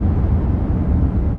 highway / oldcar / dec1.ogg